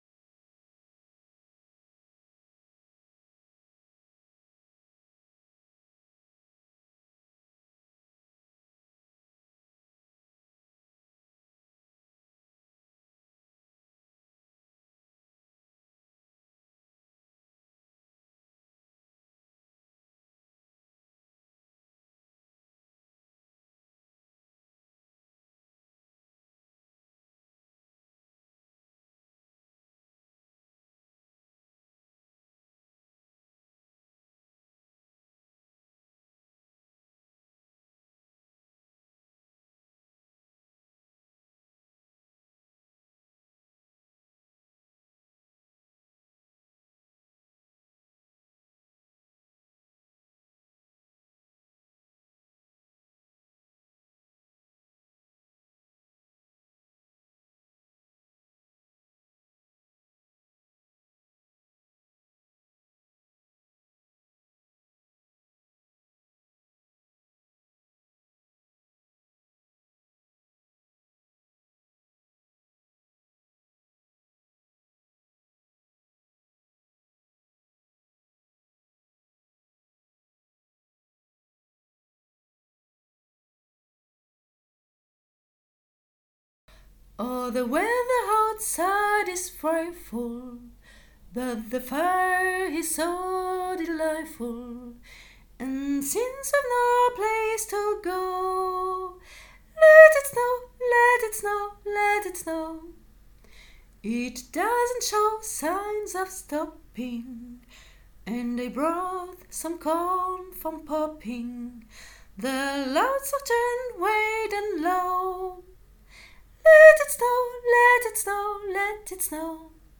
CHOEUR EPEHEMERE 2024
Let it snow thème